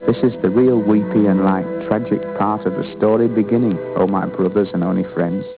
Una raccolta di clip audio dal film